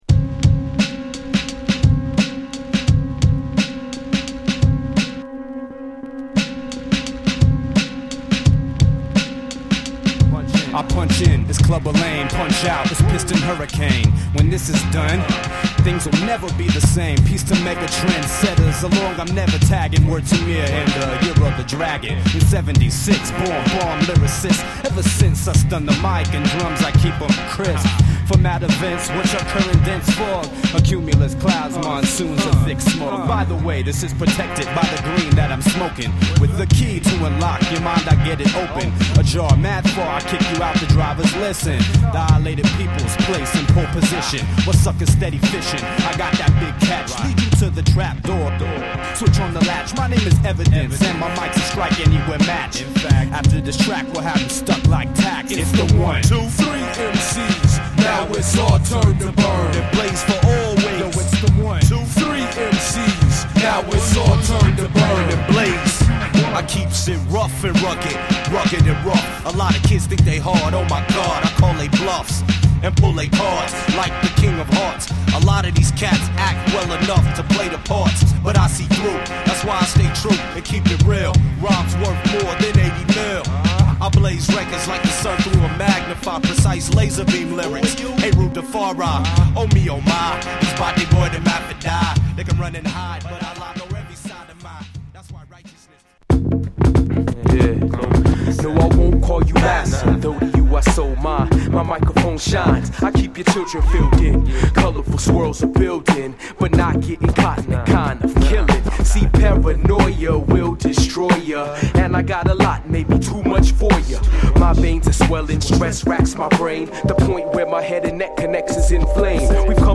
アーシーなパーカッションも際立った
まだまだ荒削りな2MCのライミングと、アングラ変革期のこの時代らしいストイックさが相俟ったカッコ良い1枚